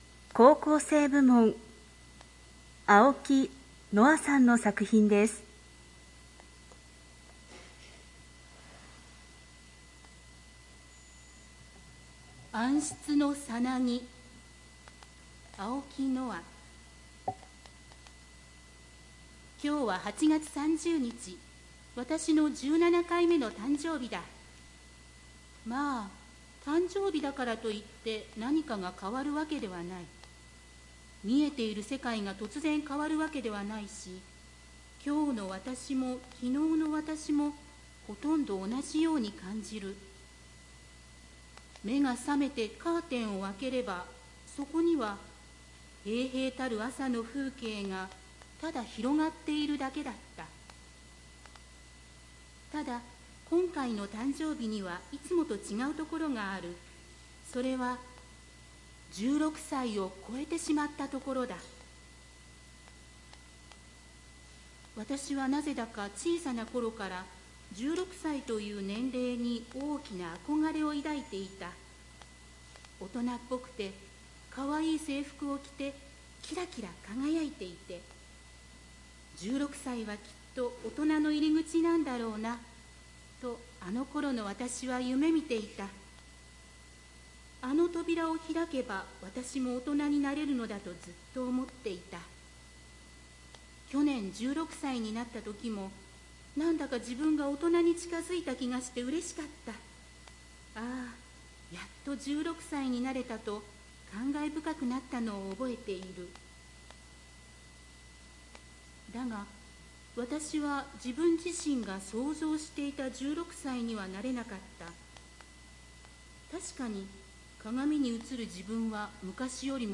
最優秀賞作品の朗読音声
〈朗読〉音訳ボランティアグループ サークルさえずりの皆さん